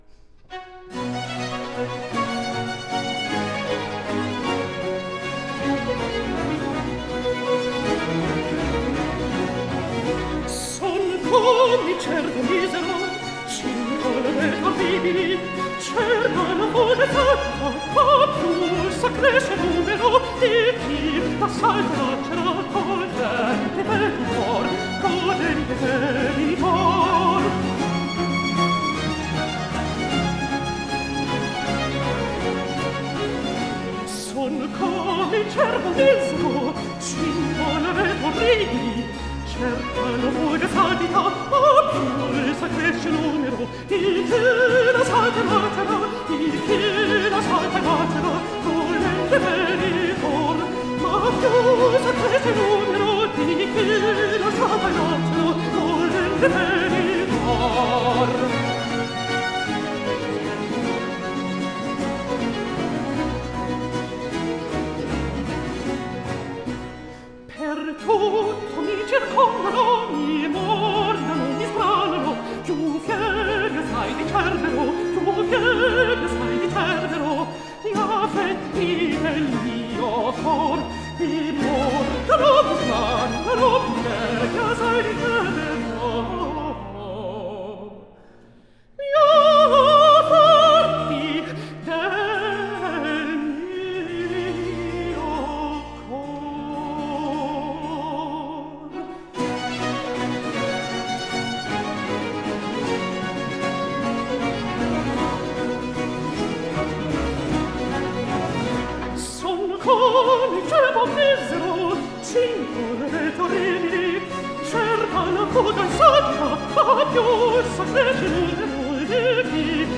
countertenor